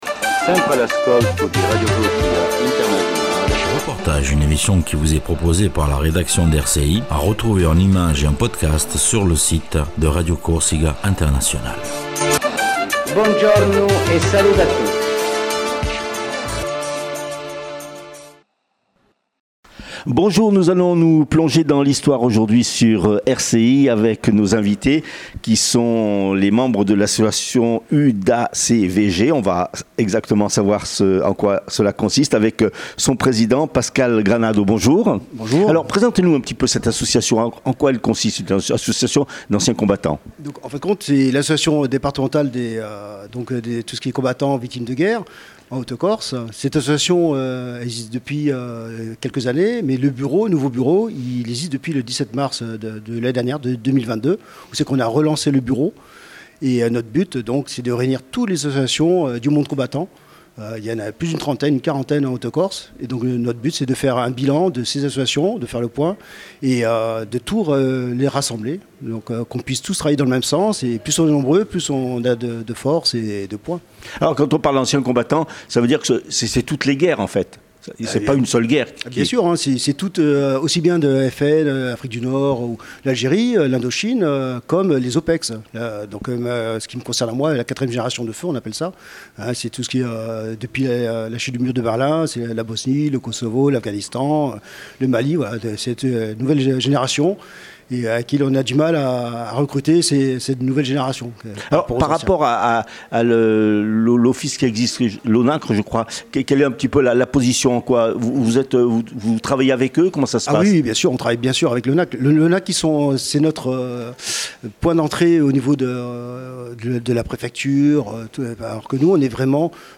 REPORTAGE SUR L'ASSOCIATION UDACVG DE CORSE